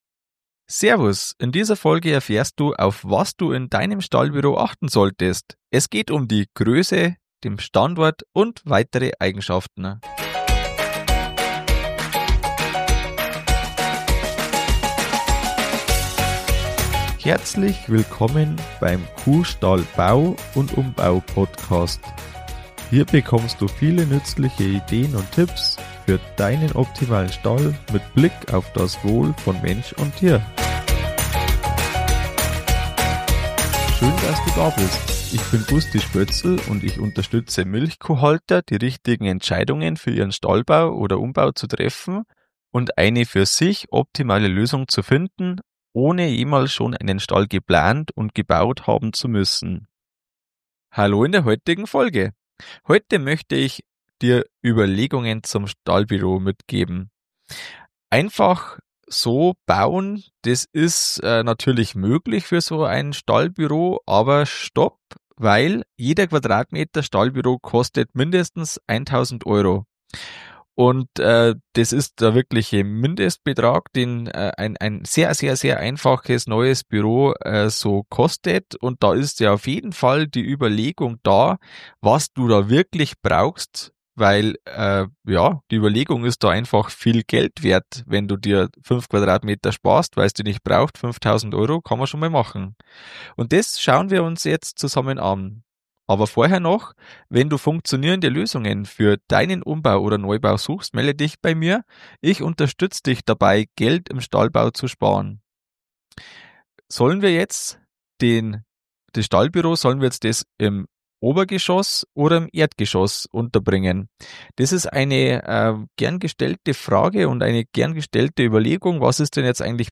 KSB 097 Der Futtertisch für hohe Futteraufnahmen - Interview